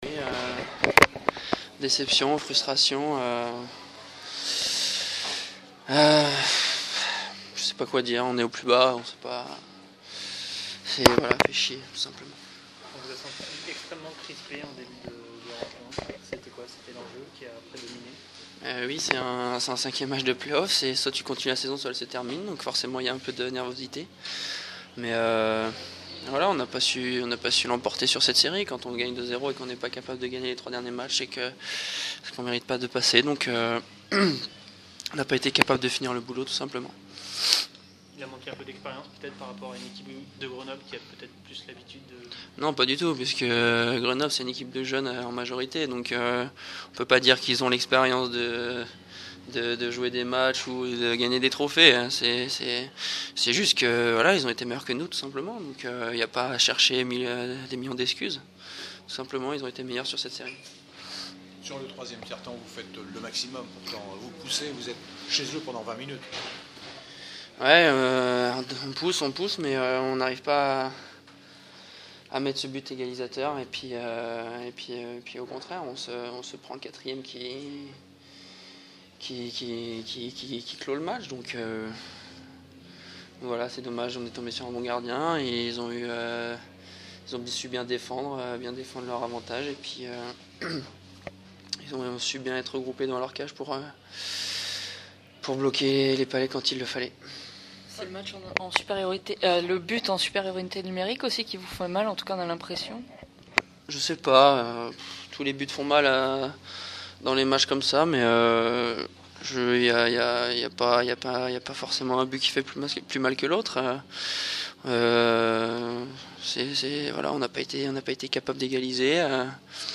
On retrouve plusieurs joueurs au micro du pool presse après la 5ème rencontre à Dijon contre Grenoble
Interview